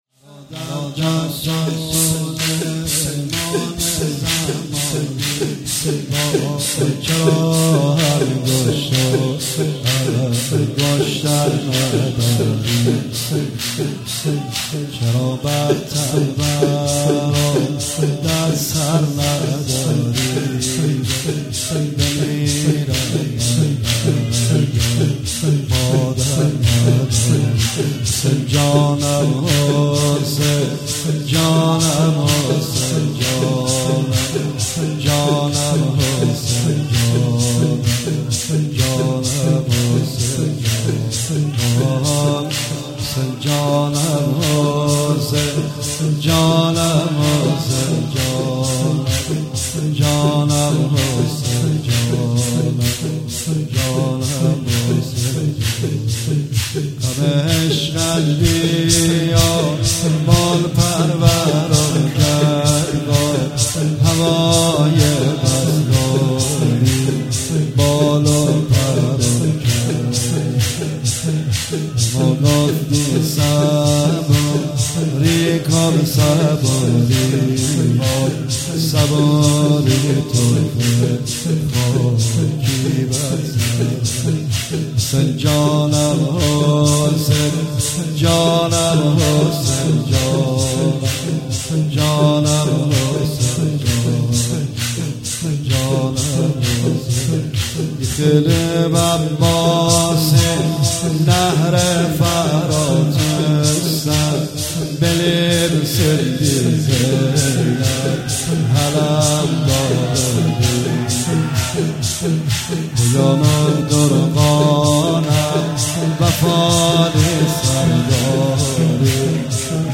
مراسم شب اول محرم 1438 هیئت رزمندگان مکتب الحسین(ع)